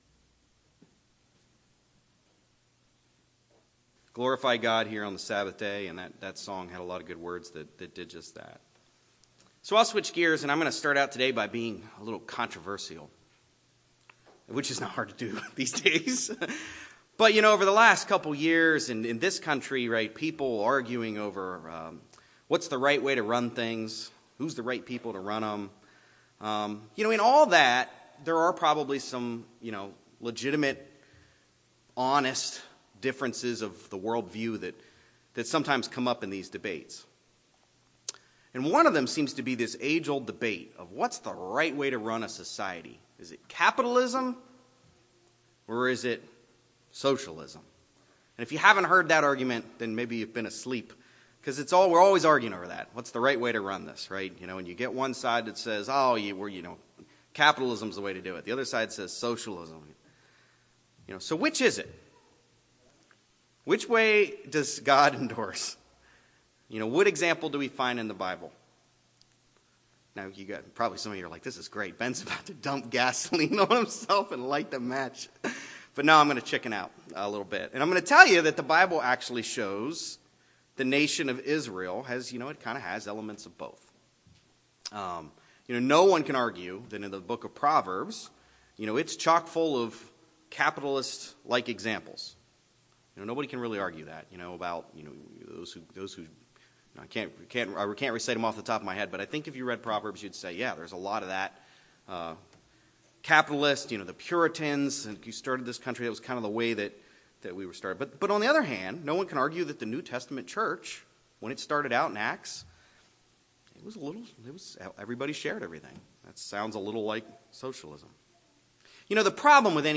Sermons
Given in Greensboro, NC Raleigh, NC